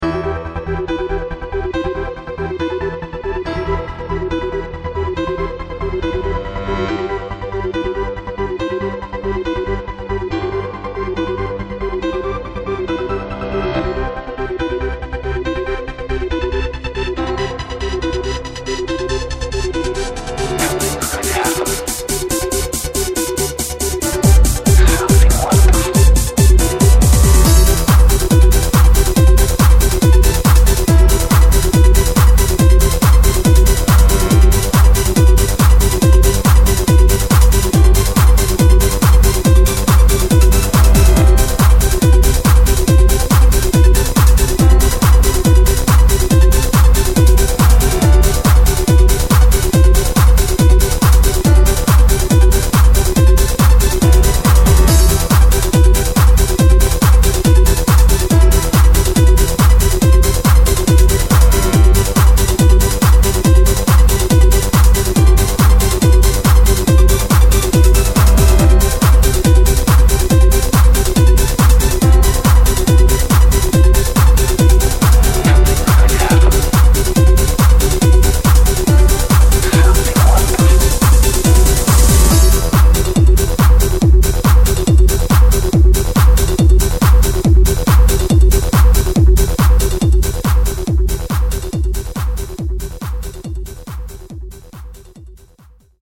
Hard-Trance, Trance